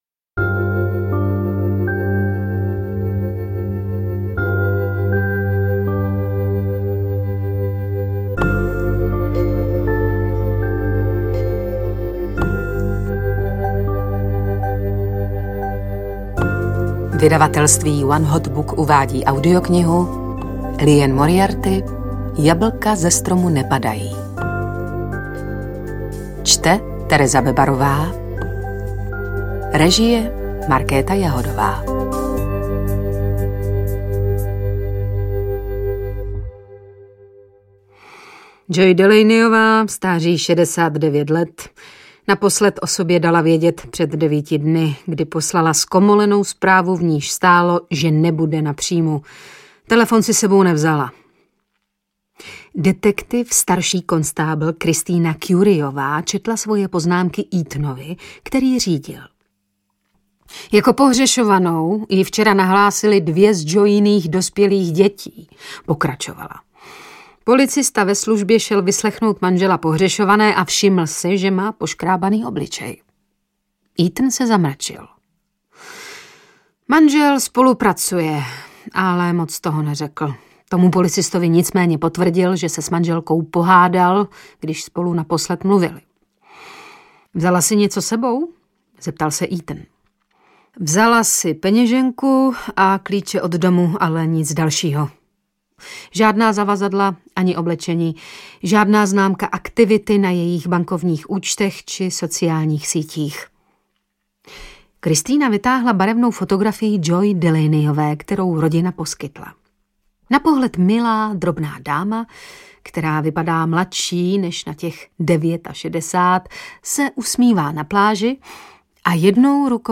Jablka ze stromu nepadají audiokniha
Ukázka z knihy
• InterpretTereza Bebarová